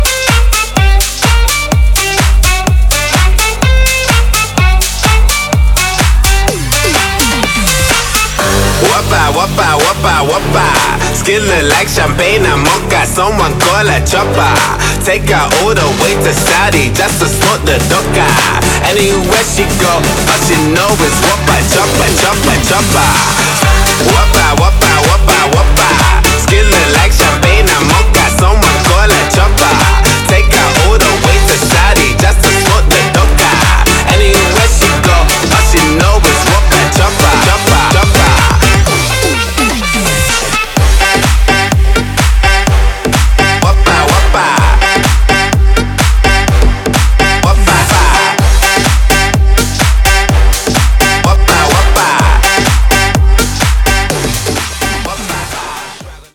• Качество: 320, Stereo
зажигательные
Club House
Latin Pop
клубная музыка
танцевальная музыка